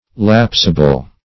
lapsable - definition of lapsable - synonyms, pronunciation, spelling from Free Dictionary Search Result for " lapsable" : The Collaborative International Dictionary of English v.0.48: Lapsable \Laps"a*ble\, a. Lapsible.